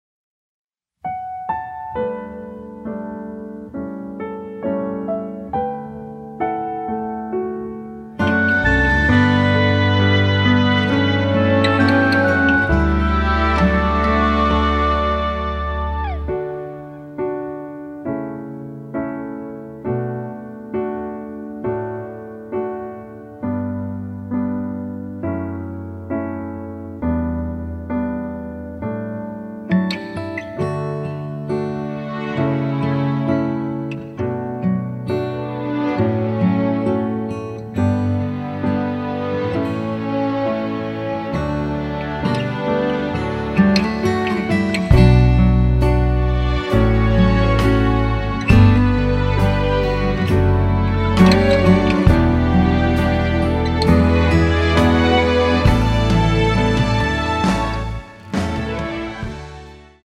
공식 음원 MR
앞부분30초, 뒷부분30초씩 편집해서 올려 드리고 있습니다.